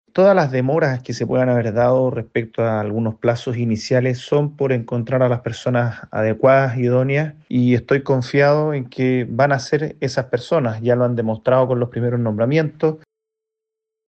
Por ello, el diputado republicano Benjamín Moreno explicó que las demoras responden a la búsqueda de las personas más adecuadas para cada cargo.